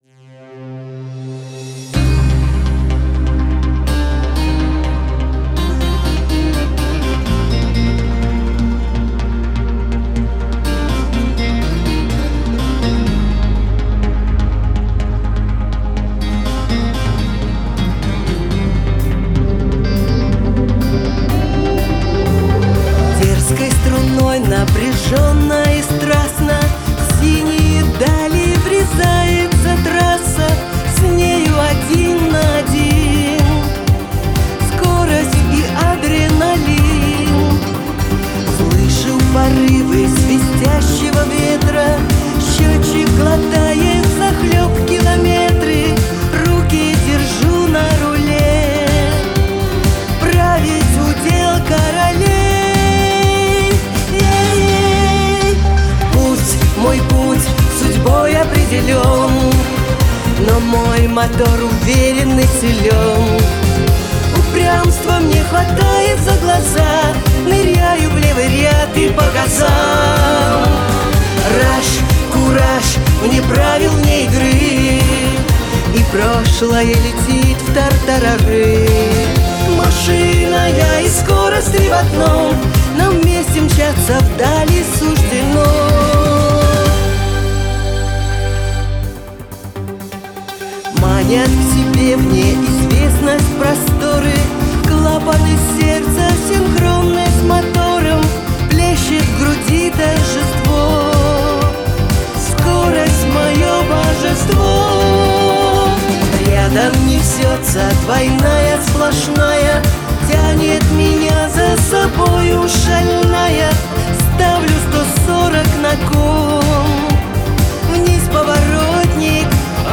вокал
гитары